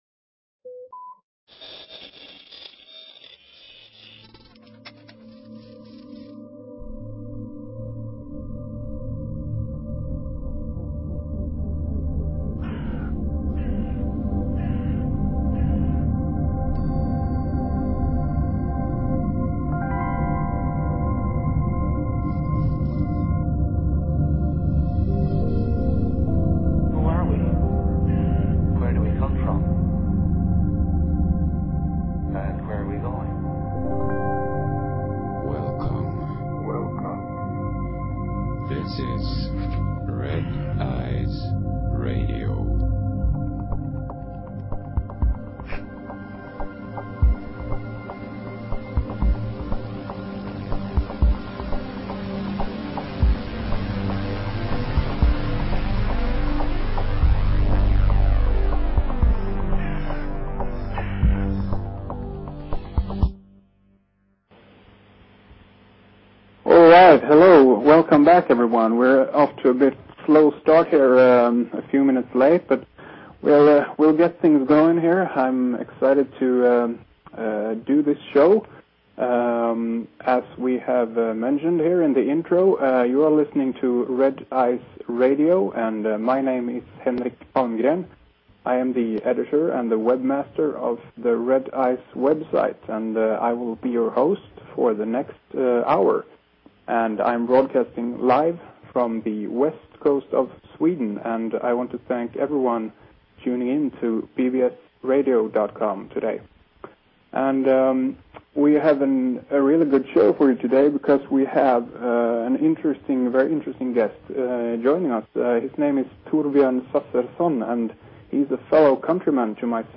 Talk Show Episode, Audio Podcast, Red_Ice_Radio and Courtesy of BBS Radio on , show guests , about , categorized as